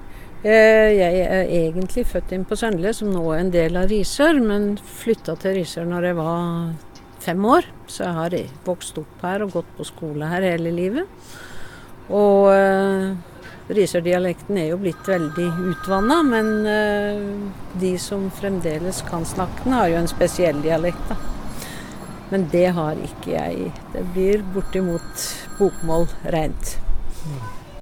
English: Dialect in Risør
Risørdialekt2.ogg